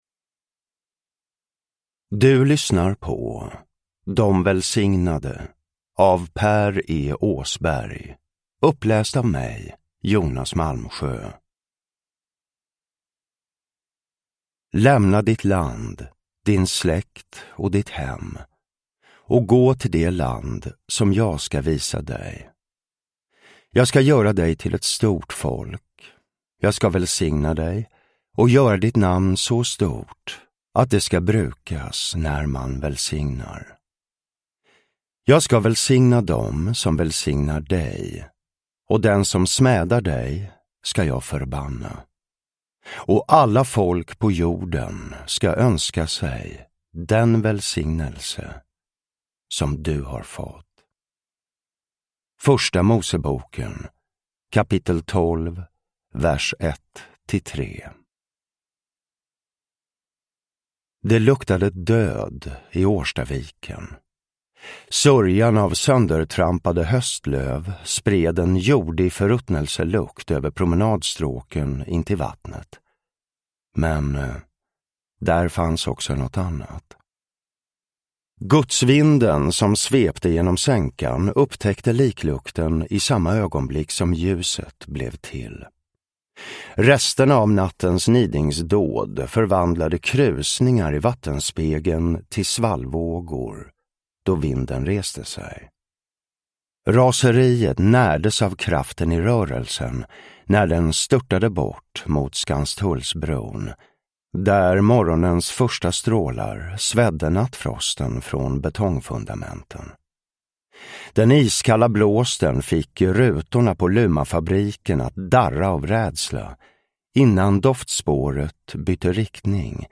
De välsignade (ljudbok) av Per E Åsberg